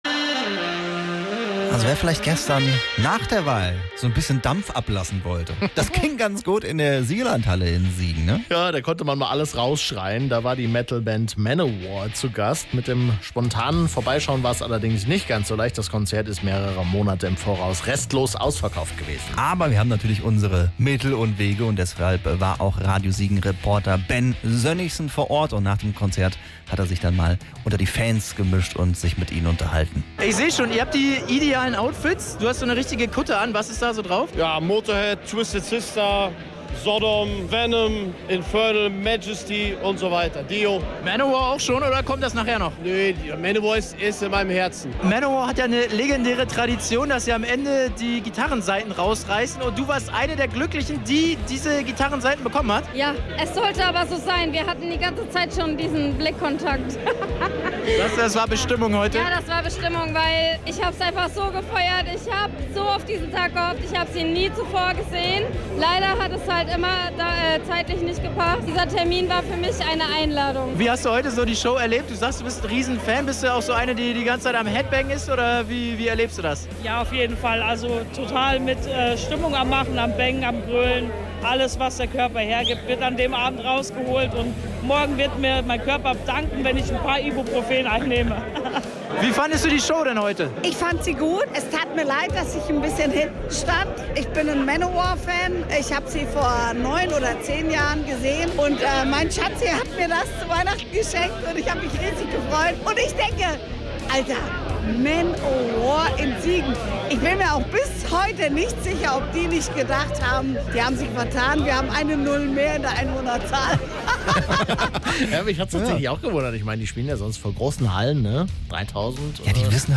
Manowar in der Siegerlandhalle. Gut 3000 Metalfans kamen Sonntagabend voll auf ihre Kosten.